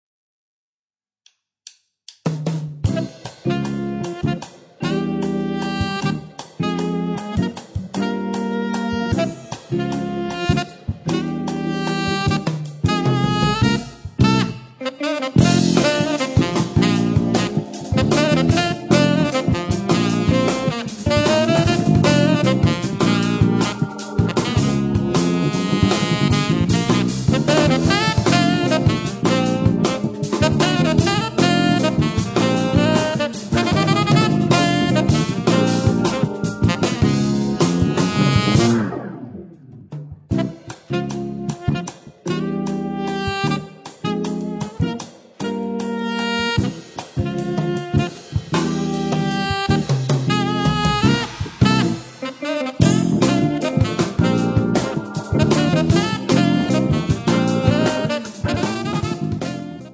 chitarra elettrica
sassofoni
basso
batteria
Hammond e tastiere